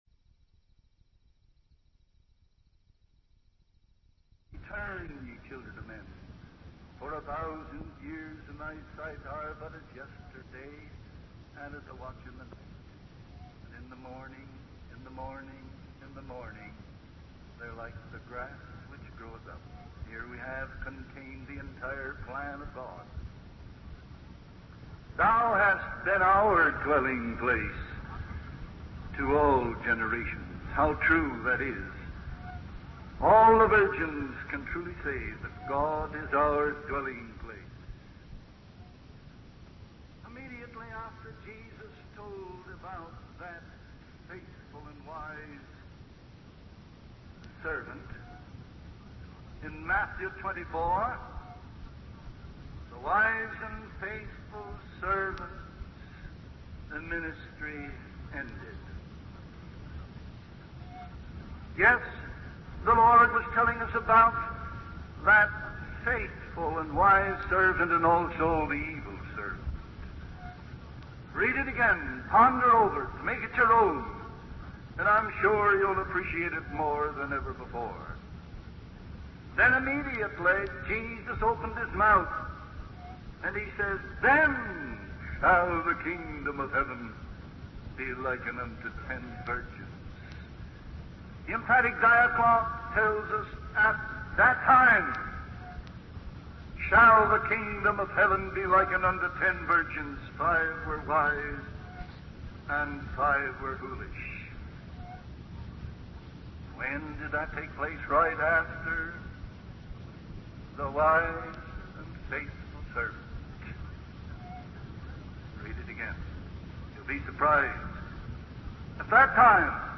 From Type: "Discourse"
Given at Bowling Green, OH